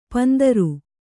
♪ pandaru